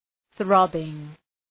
Προφορά
{‘ɵrɒbıŋ}